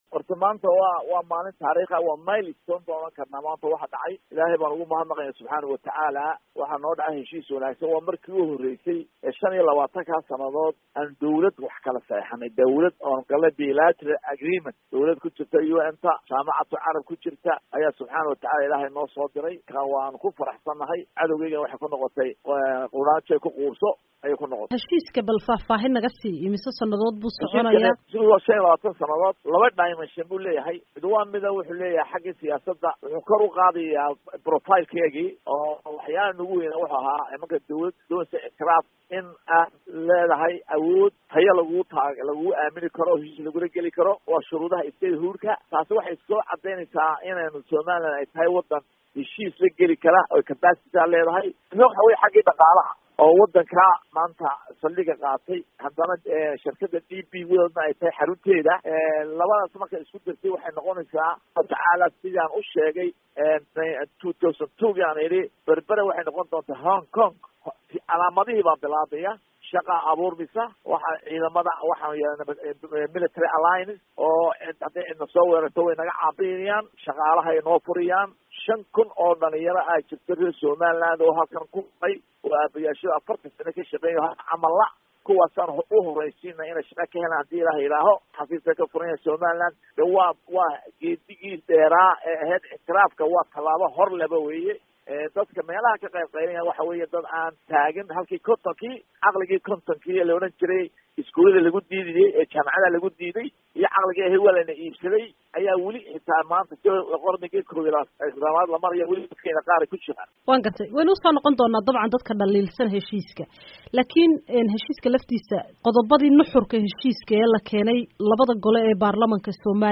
khadka telephone ka ee magalada Dubai kula xidhiidhay